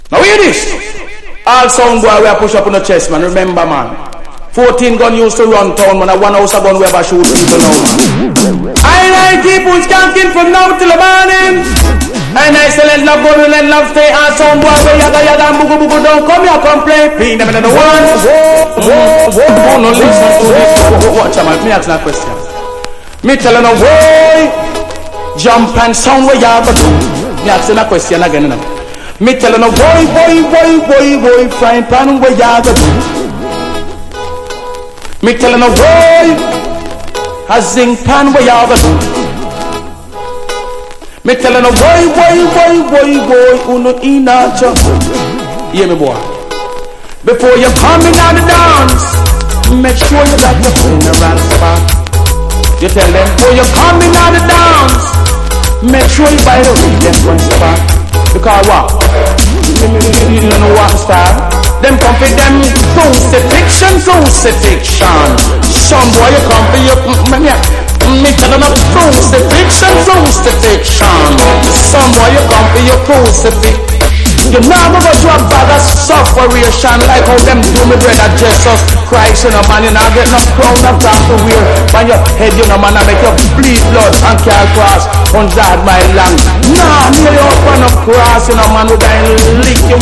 • REGGAE-SKA
DANCE HALL